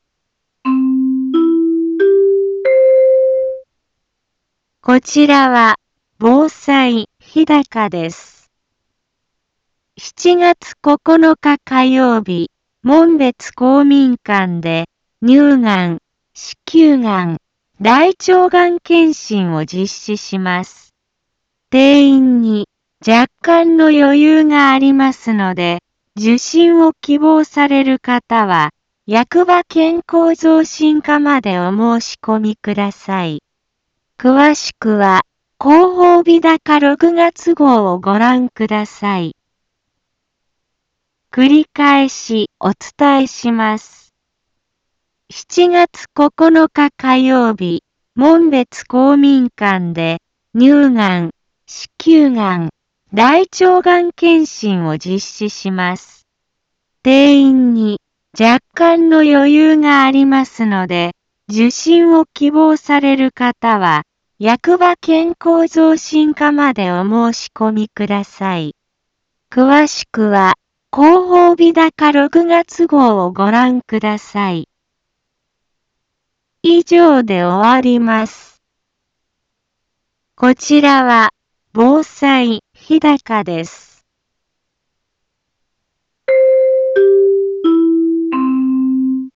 BO-SAI navi Back Home 一般放送情報 音声放送 再生 一般放送情報 登録日時：2019-06-24 15:03:10 タイトル：乳がん・子宮がん・大腸がん検診のお知らせ インフォメーション：7月9日火曜日門別公民館で、乳がん・子宮がん・大腸がん検診を実施します。